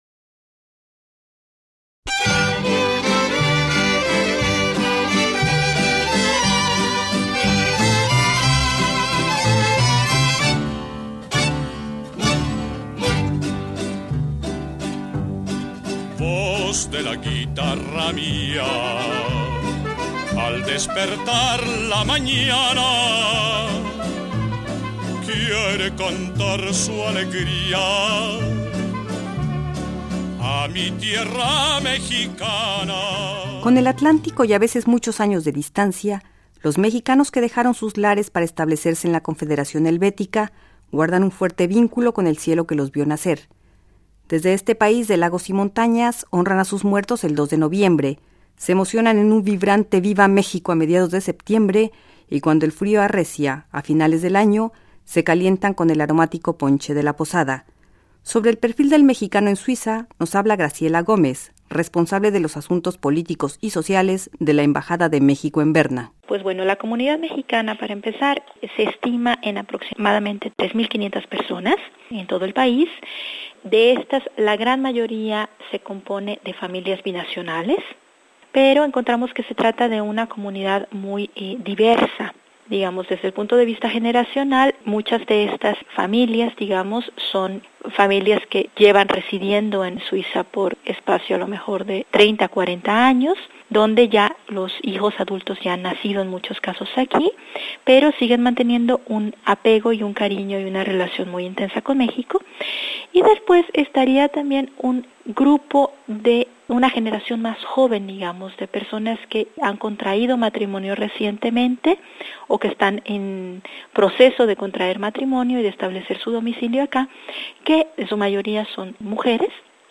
Los mexicanos en Suiza forman una colonia organizada y sin mayores problemas de integración pese a la barrera lingüística. En un 90% integran matrimonios binacionales, y aunque sus raíces quedaron allende el mar, mantienen fuertes vínculos con su tierra natal. Reportaje